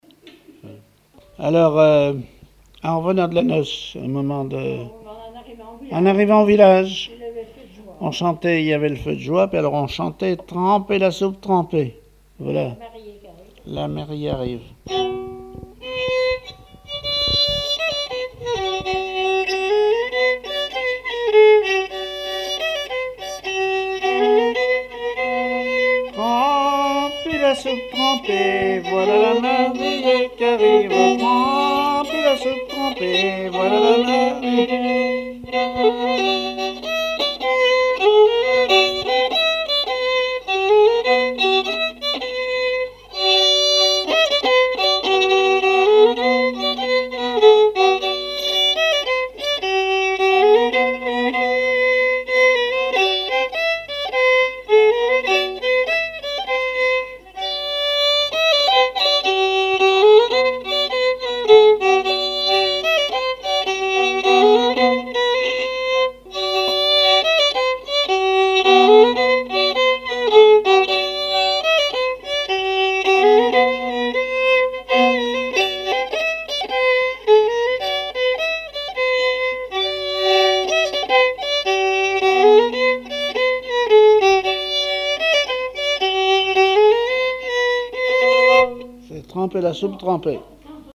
Genre strophique
répertoire musical au violon
Pièce musicale inédite